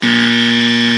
Wrong Answer Buzzer